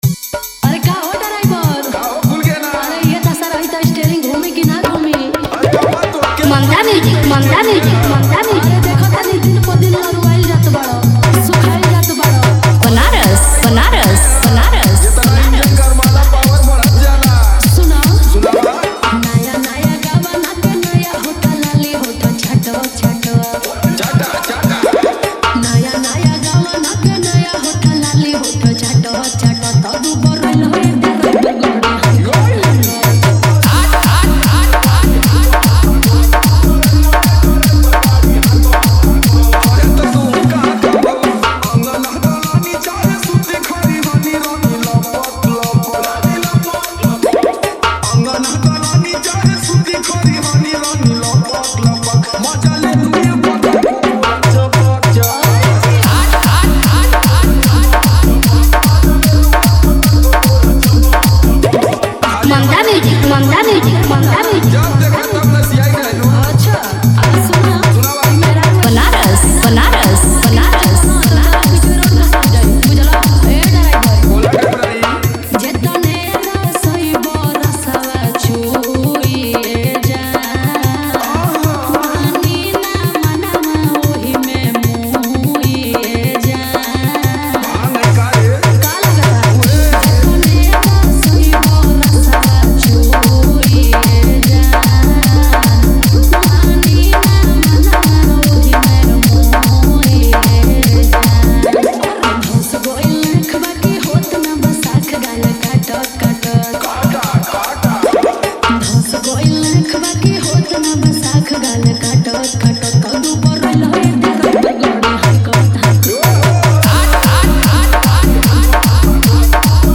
Category : dj remix songs bhojpuri 2025 new